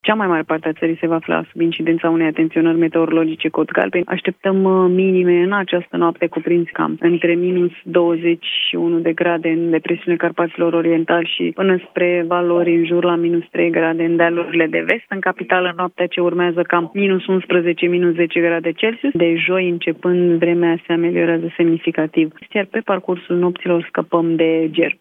Meteoroloaga